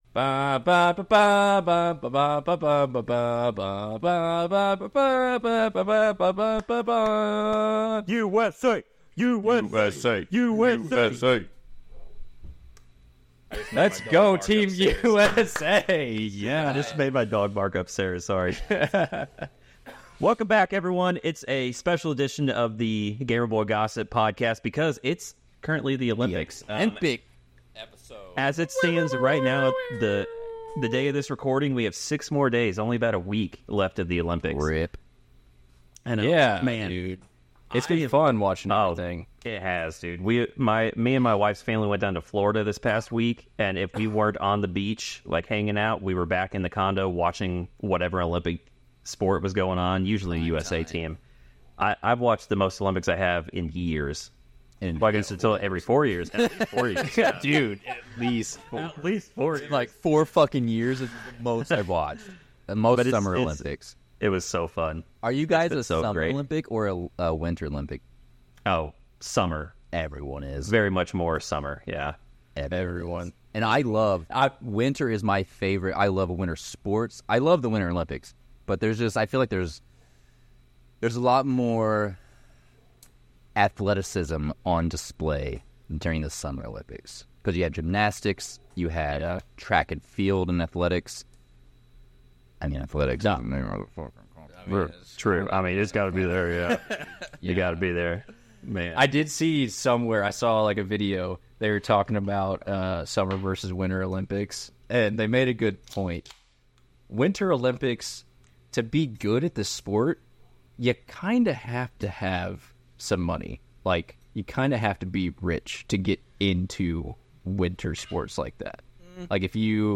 We're three brothers bringing entertainment to your earholes every single week. We'll cover topics like gaming, pop culture, sports, life, and whatever else pops up in our conversations.